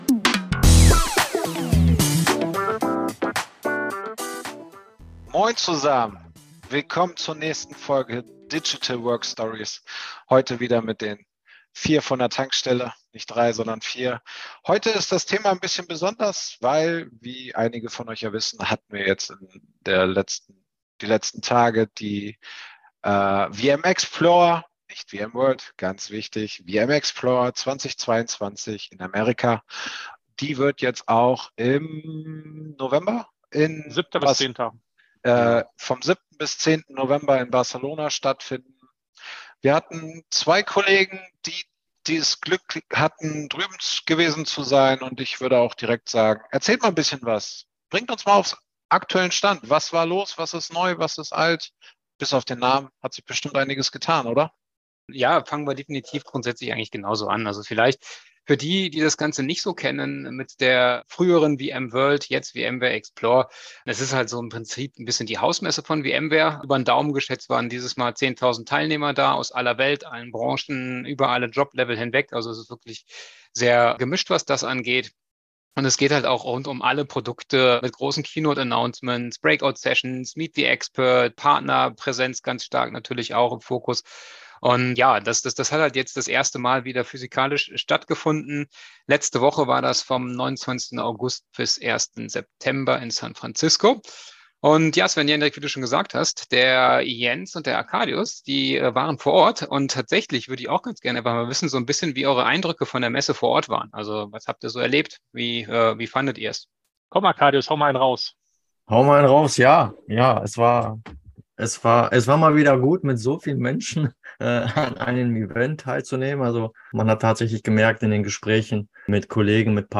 In Folge 4 sprechen eure vier Jungs von der EUC-Stelle über das jährliche VMware Klassentreffen: Die VMware Explore. Erfahrt was es neues aus der Welt des Enduser Computing gibt, wie die erste vor-Ort Messe seit über zwei Jahren gelaufen ist und was noch spannendes in den kommenden Wochen passieren wird.